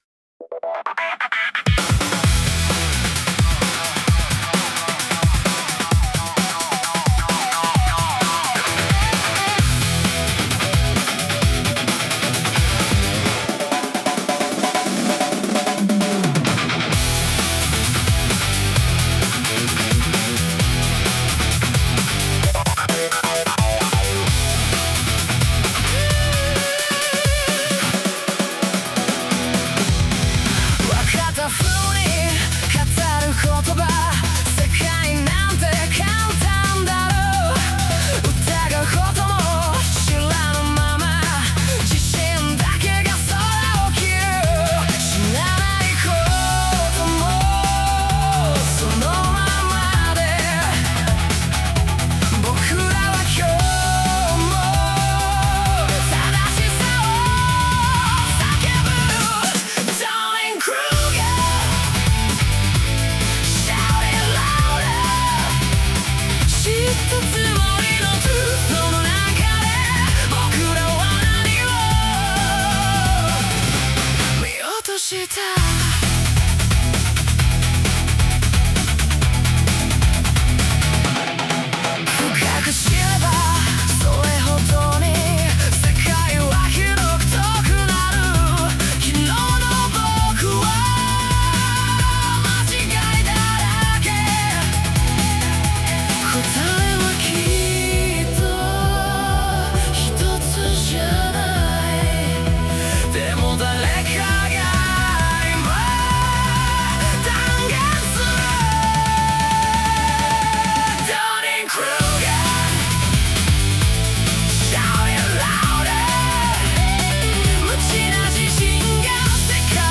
男性ボーカル
イメージ：130BPM,ブレイクビート・エレクトロニック・ロック,男性ボーカル